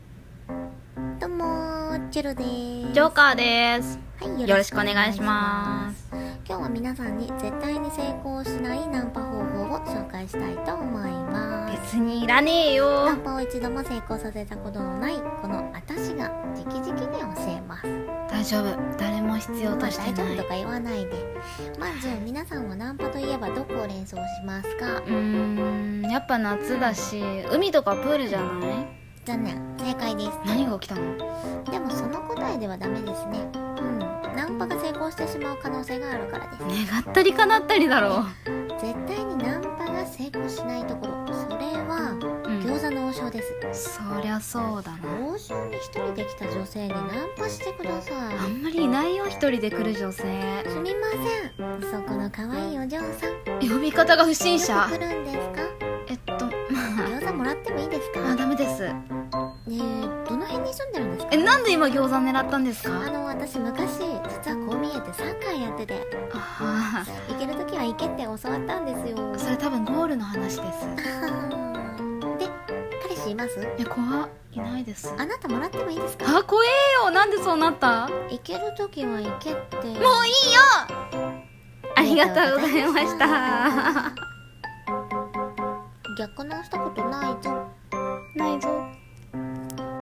【二人声劇】 漫才 絶対成功しないナンパ法